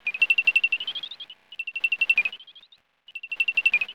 Vrápenec malý
Rhinolophus hipposideros
Záznam echolokace v systému heterodyning
Echolokační signály jsou velmi tiché, dlouhé a vysoké a největší hlasitost mají na frekvenci 106–114 kHz.